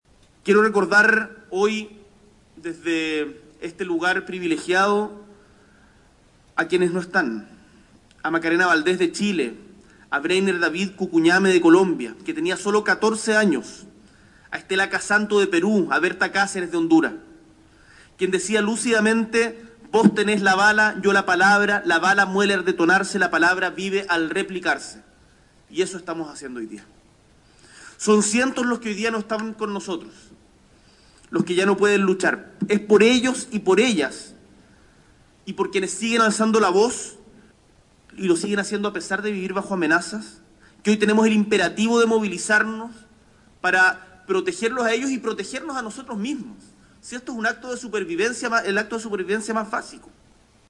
La ceremonia inaugural fue encabezada por el Presidente de la República, Gabriel Boric, quien firmó el 18 de marzo el proyecto de ley que buscaba sumar a Chile al acuerdo ambiental.
En la ceremonia, Boric enfatizó en la importancia de que Latinoamérica enfrente la situación medioambiental en conjunto y señaló que “cuando se quema una parte del Amazonas o cuando se acelera el derretimiento de un glaciar en la Patagonia chilena o argentina, no es el Estado o el presidente de turno el que sufre, es toda la humanidad”.